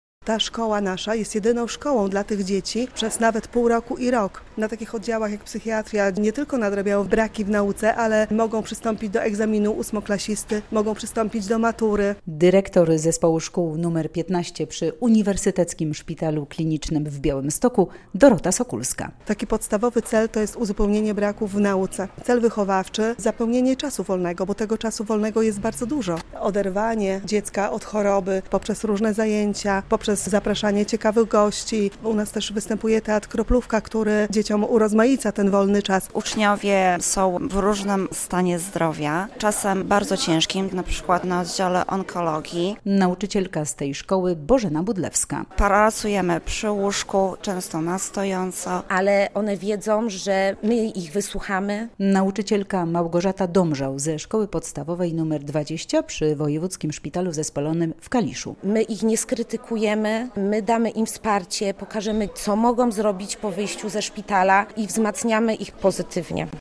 Znaczenie szkół szpitalnych w edukacji i terapii dzieci i młodzieży -relacja